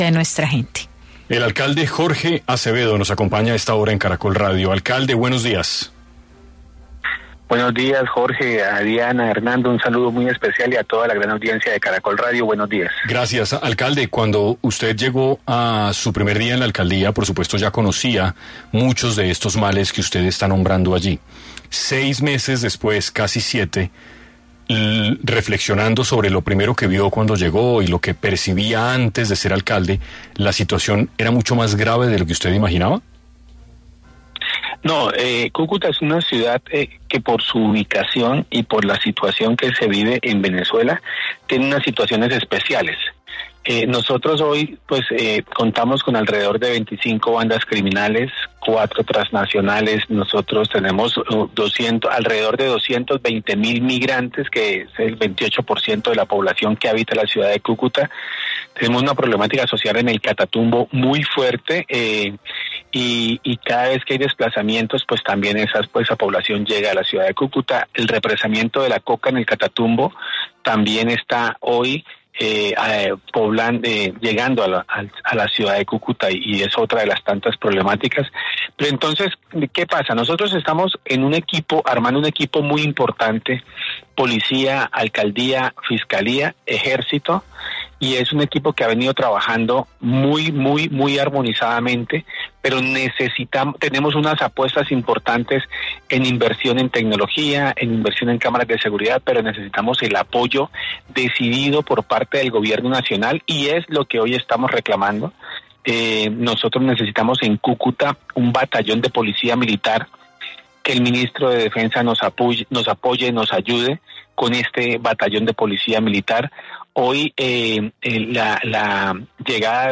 En 6AM Hoy por Hoy de Caracol Radio estuvo Jorge Acevedo, alcalde de Cúcuta, para hablar sobre qué necesita la ciudad ante el recrudecimiento de la violencia en la región y cuál es la situación de orden público en zona fronteriza.